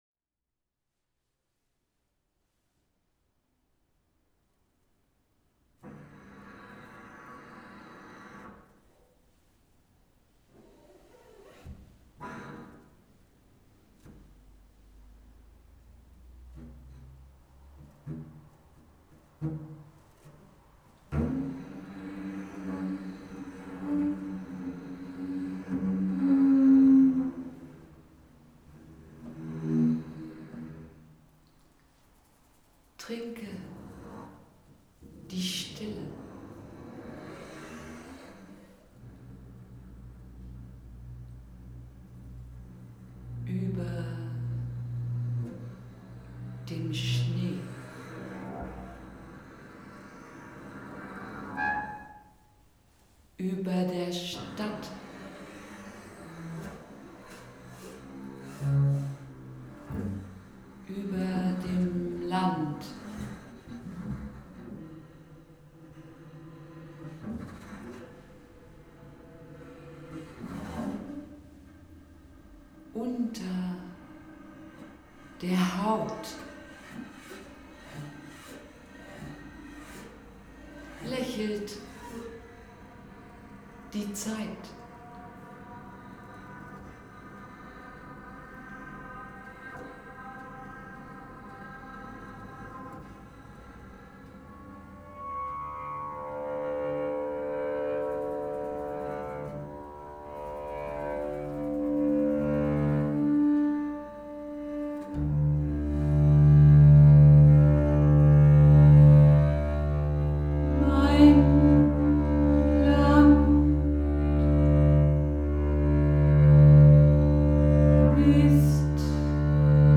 Kontrabassisten